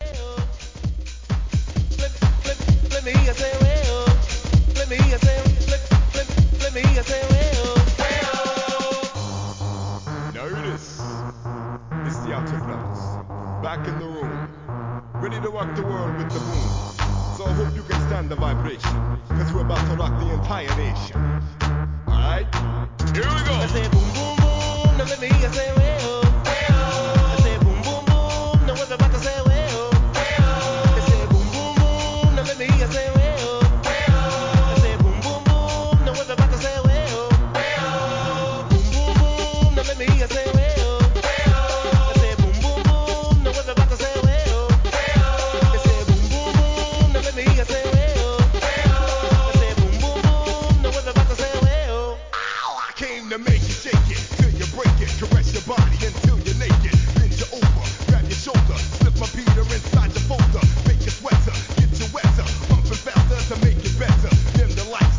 HIP HOP/R&B
癖になるフックで1995年のDISCO HIT!!